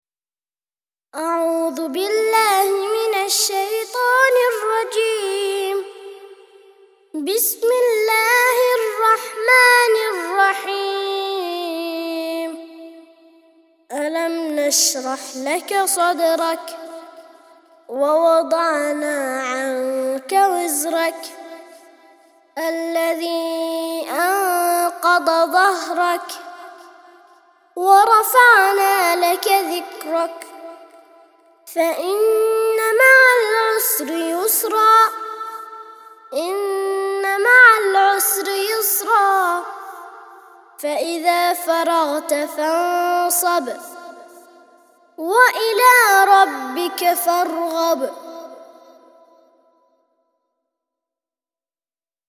94- سورة الشرح - ترتيل سورة الشرح للأطفال لحفظ الملف في مجلد خاص اضغط بالزر الأيمن هنا ثم اختر (حفظ الهدف باسم - Save Target As) واختر المكان المناسب